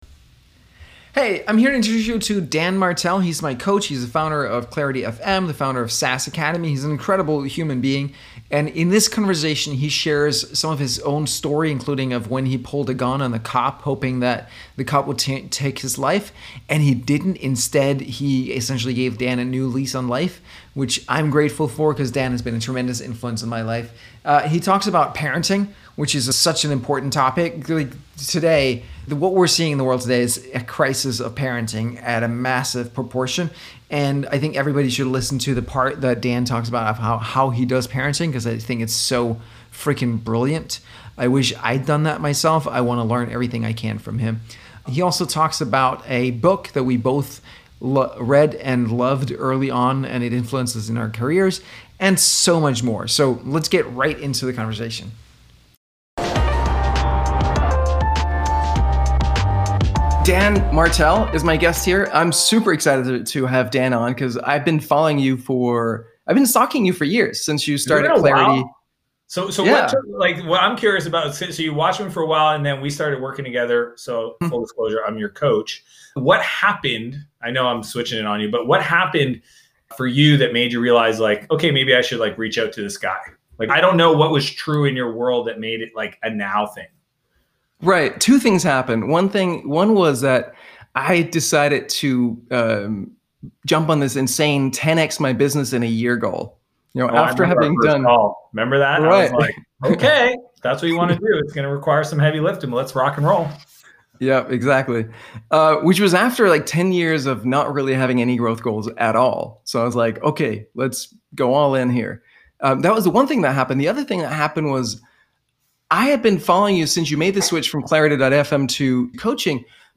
How To Not Make Entitled Brats Interview